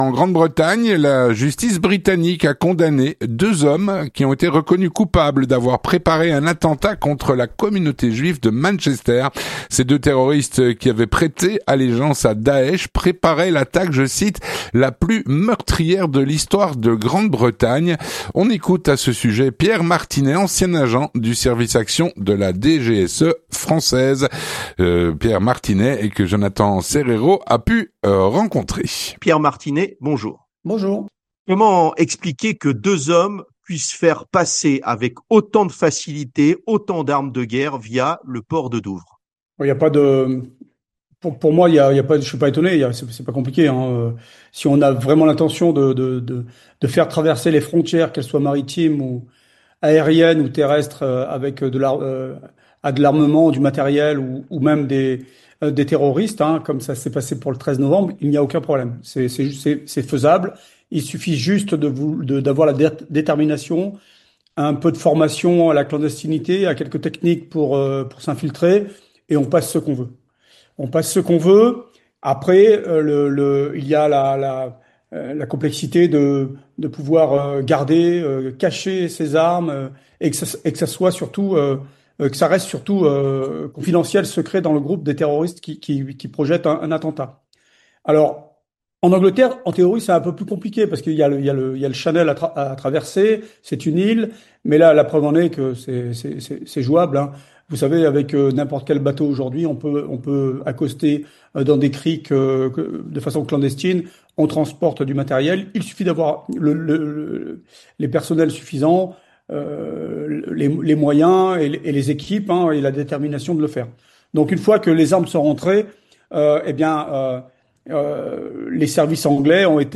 L'entretien du 18H - La justice britannique a condamné deux hommes reconnus coupables d’avoir préparé un attentat contre la communauté juive de Manchester.